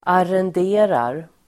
Uttal: [arend'e:rar]